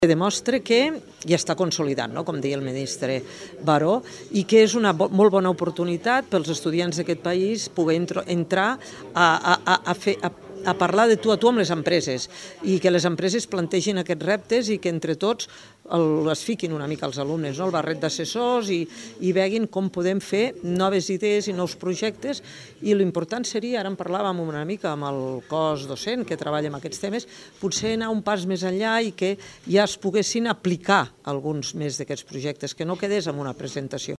Al seu torn, la ministra de Treball Conxita Marsol, ha apuntat que l’objectiu seria anar “un pas més enllà” perquè alguns projectes es puguin materialitzar i generar un impacte real.